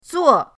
zuo4.mp3